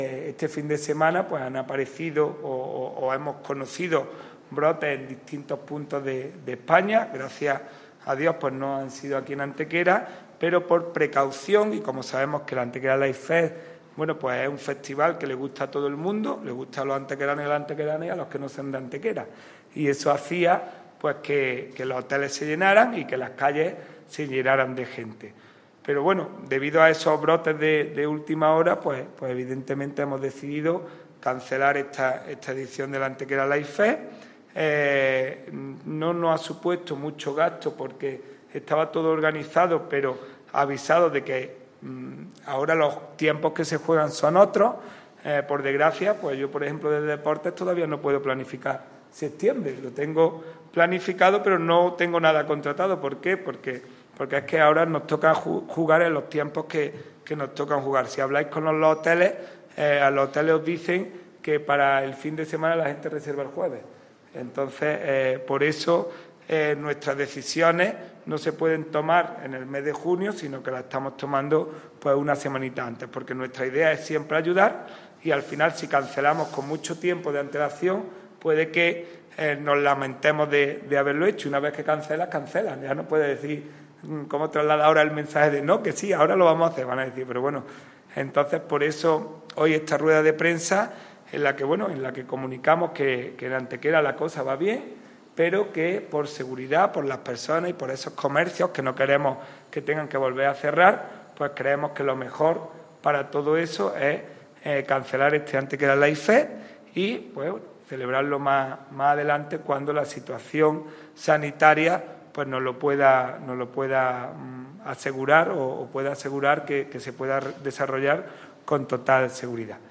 El alcalde de Antequera, Manolo Barón, y el teniente de alcalde delegado de Patrimonio Mundial, Juan Rosas, han anunciado en la mañana de hoy viernes en rueda de prensa la cancelación del Antequera Light Fest (ALF), festival de nuevas tecnologías, luz y sonido que cada año a mediados del mes de julio conmemora en nuestra ciudad la declaración del Sitio de los Dólmenes como Patrimonio Mundial de la UNESCO.
Cortes de voz